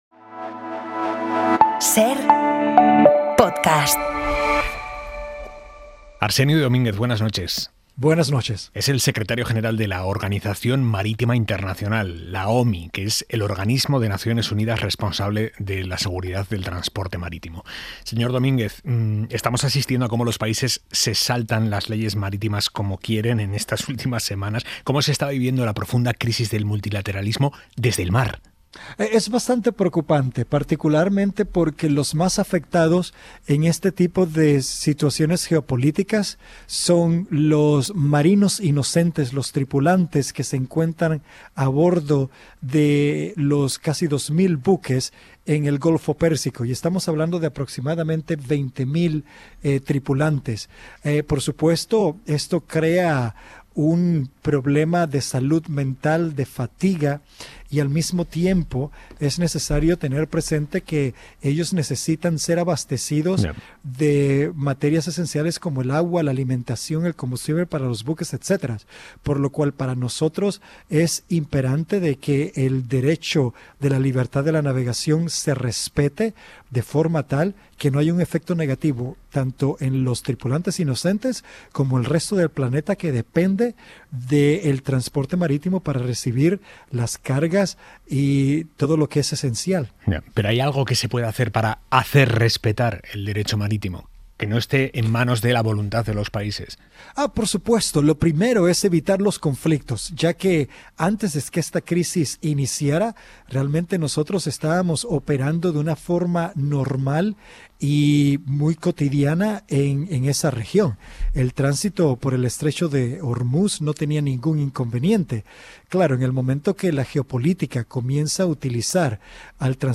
Aimar Bretos entrevista a Arsenio Domínguez, Secretario General de la Organización Marítima Internacional (OMI), el organismo de Naciones Unidas responsable de la seguridad del transporte marítimo.